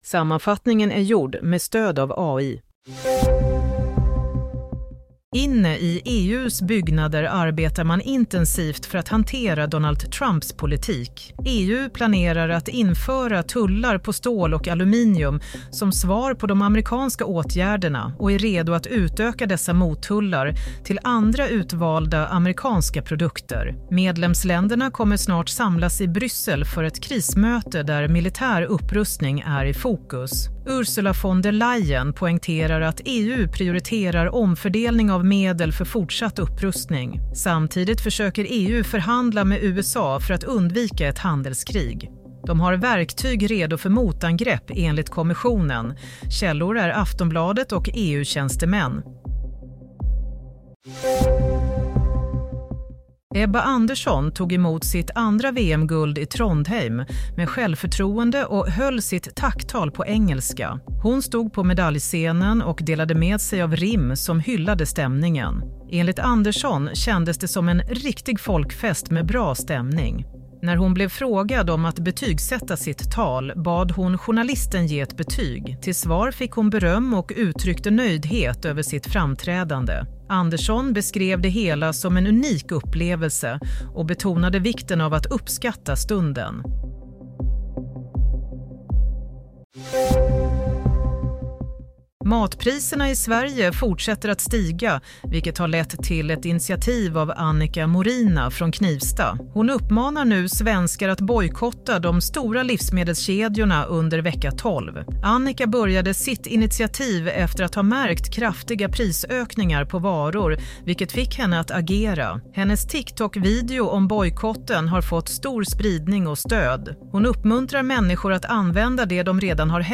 Nyhetssammanfattning - 4 mars 22.00
Sammanfattningen av följande nyheter är gjord med stöd av AI.